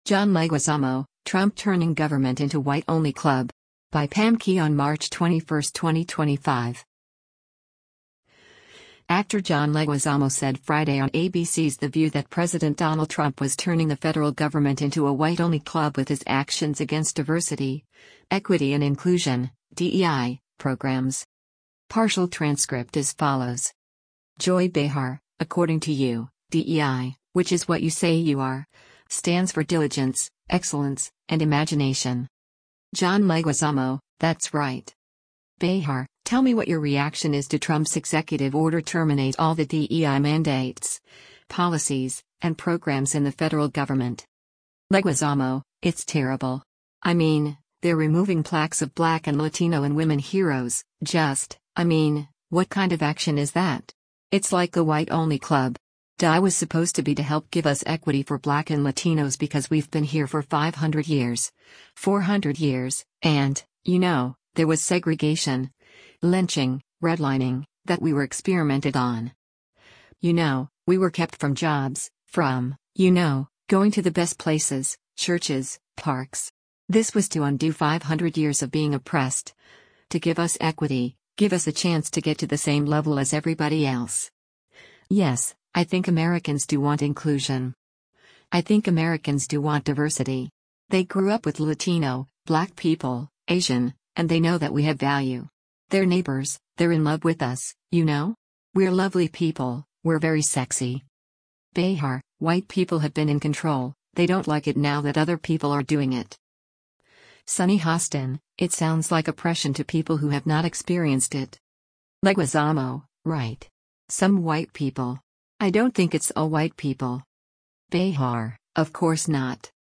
Actor John Leguizamo said Friday on ABC’s “The View” that President Donald Trump was turning the federal government into a ‘”white-only club” with his actions against Diversity, Equity and Inclusion (DEI) programs.